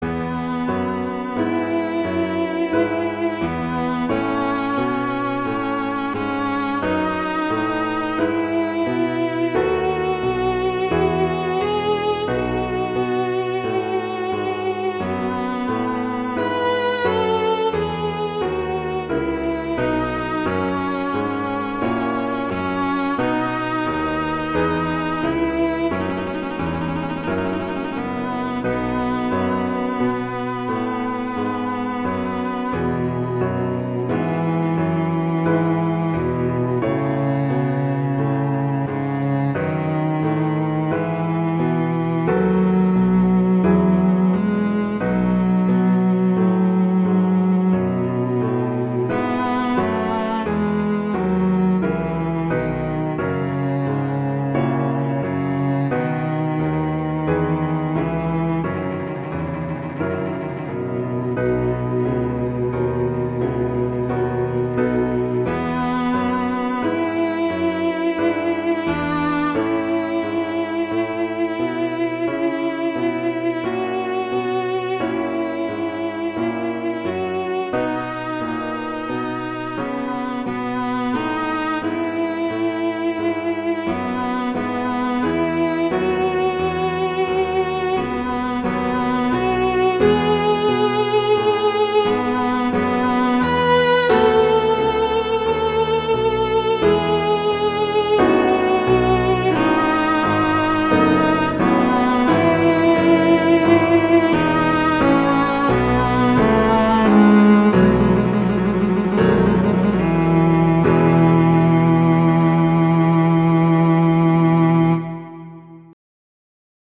cello and piano
classical
Largo cantabile